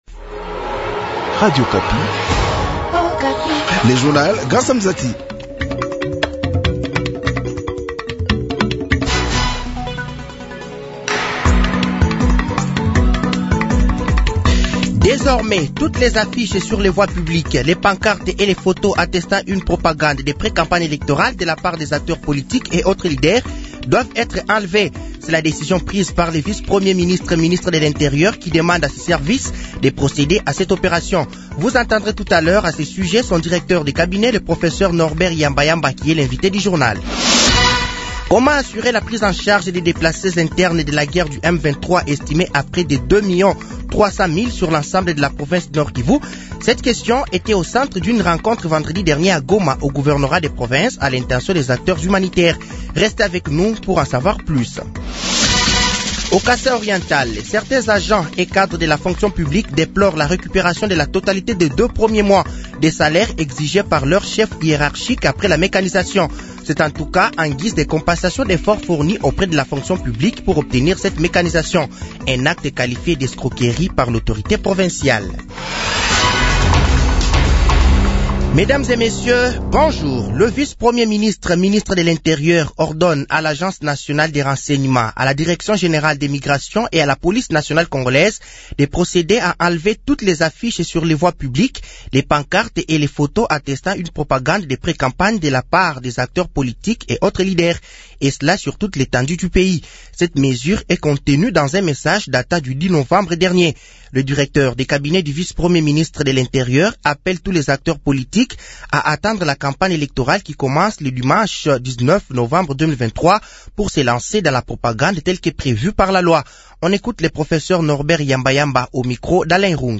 Journal français de 6h de ce lundi 13 novembre 2023